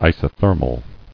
[i·so·ther·mal]